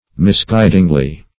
-- Mis*guid"ing*ly , adv.
misguidingly.mp3